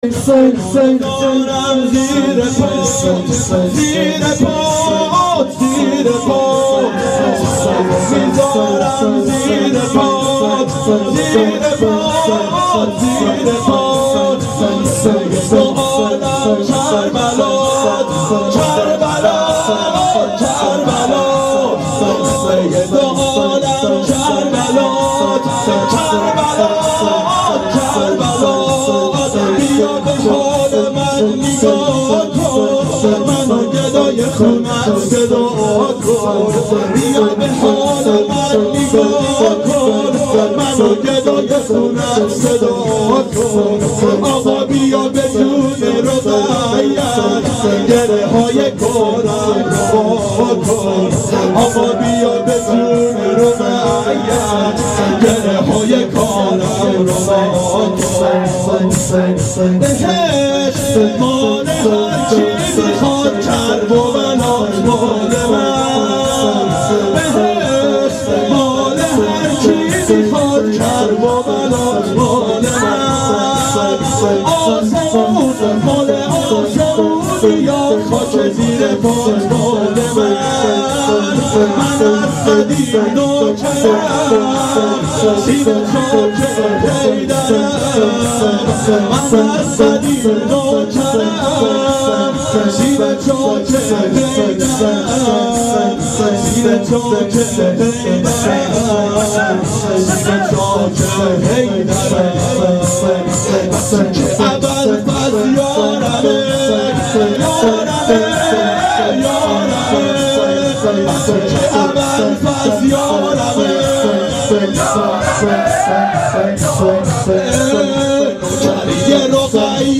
• شب سوم محرم 92 هیأت عاشقان اباالفضل علیه السلام منارجنبان